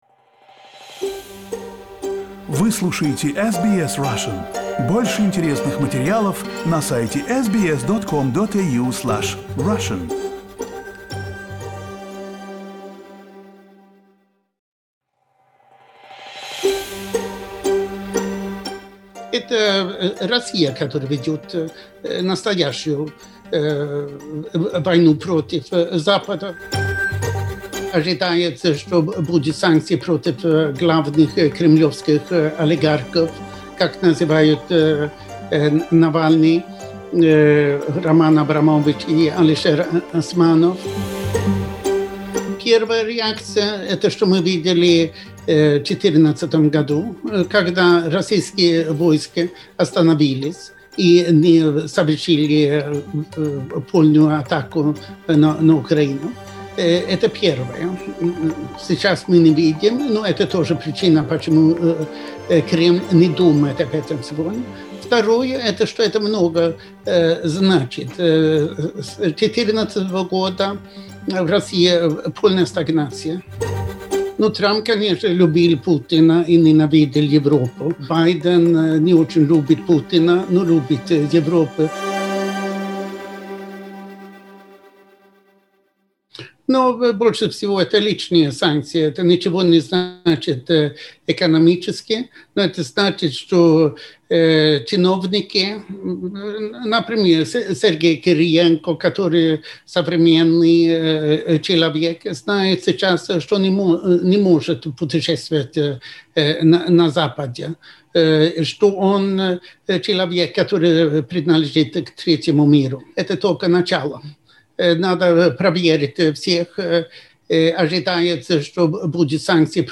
Интервью c Андерсом Аслундом, шведским экономистом и дипломатом, живущим в Вашингтоне.